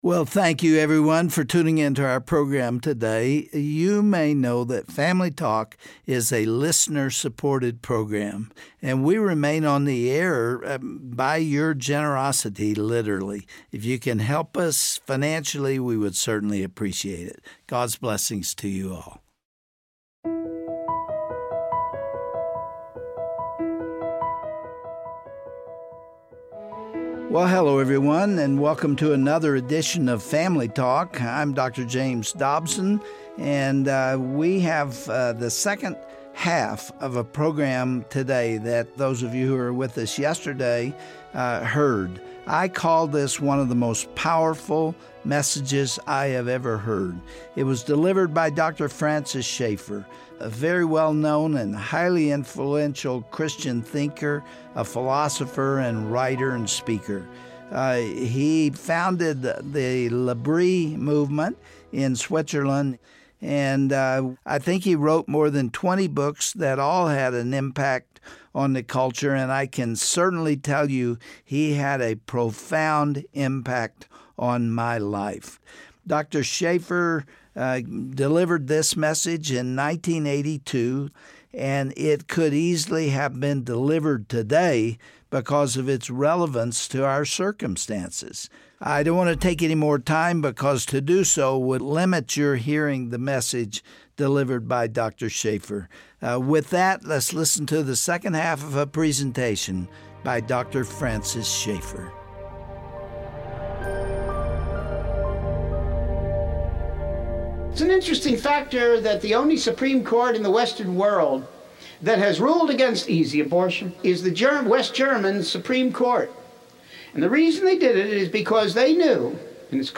Host Dr. James Dobson
Guest(s):Dr. Francis Schaeffer